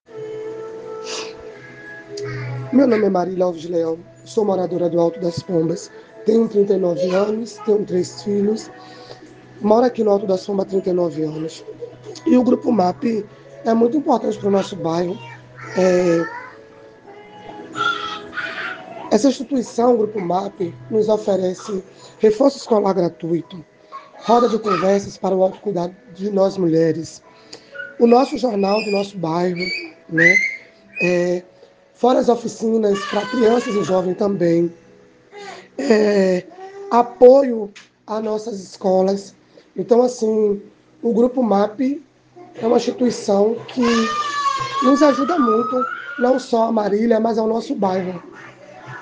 Depoimento em áudio